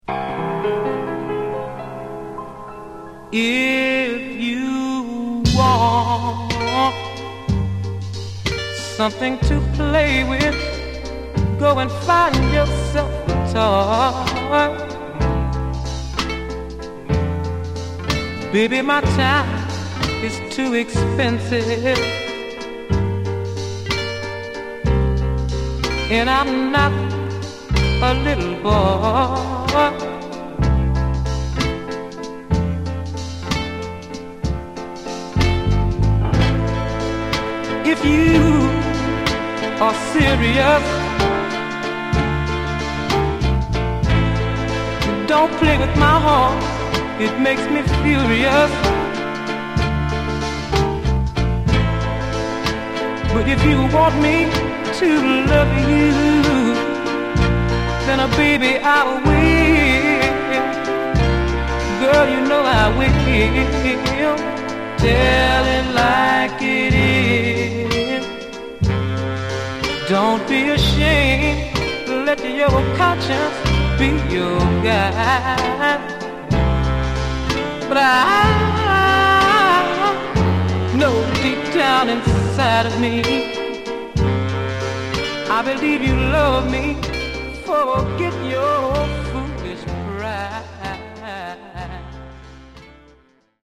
Genre: Northern Soul, Philly Style